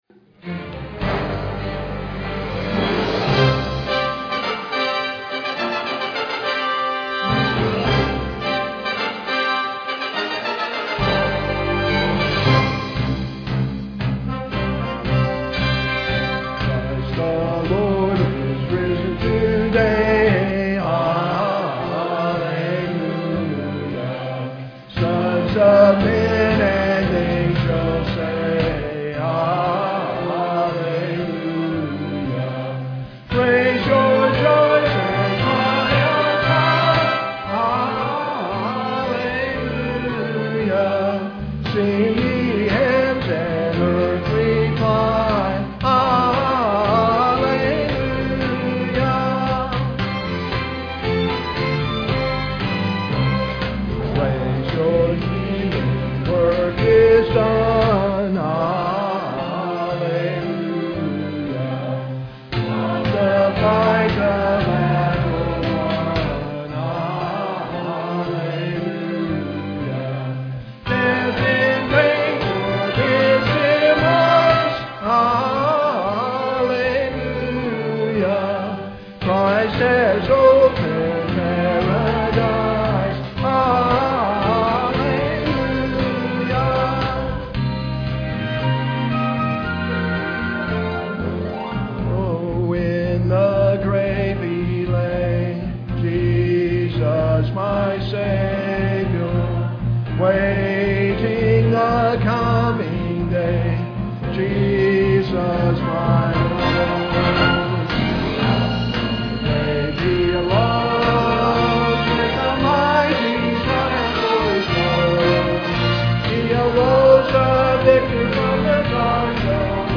PLAY The First Sunday Worship